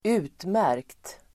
Uttal: [²'u:tmär:kt]